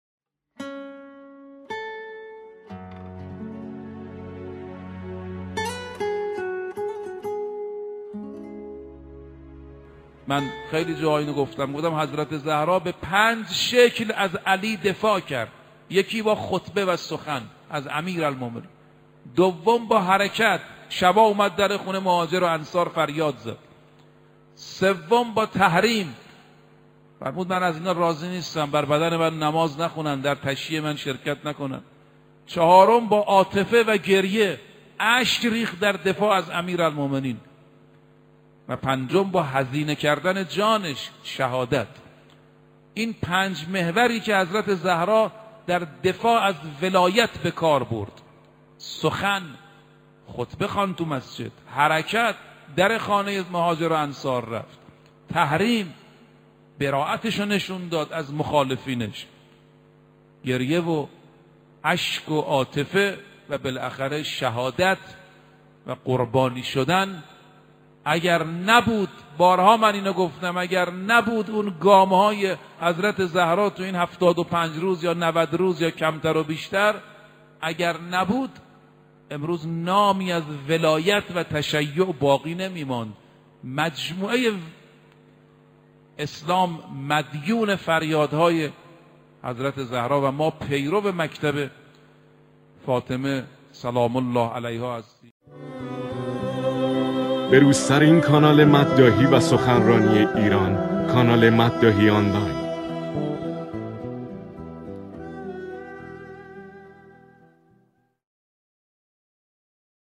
آخرین خبر/ سخنرانی بسیار شنیدنی از حجت الاسلام رفیعی درباره پنج روش حضرت زهرا (س) برای دفاع از ولایت را بشنوید.